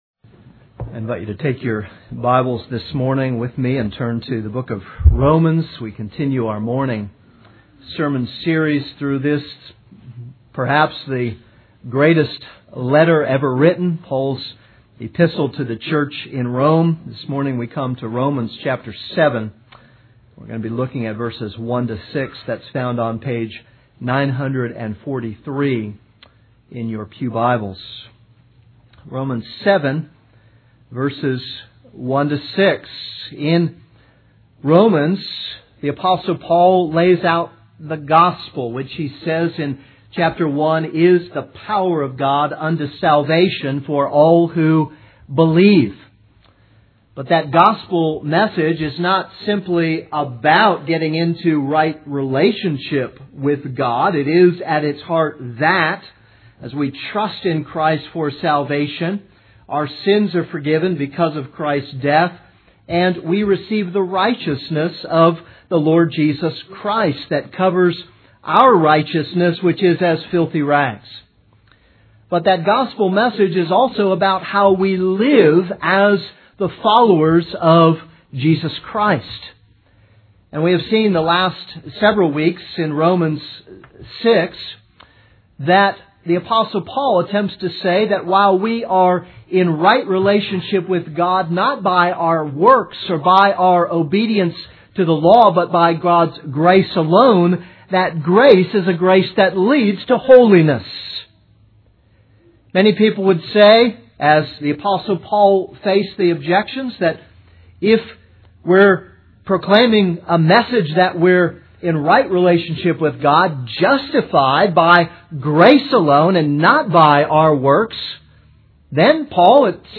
This is a sermon on Romans 7:1-6.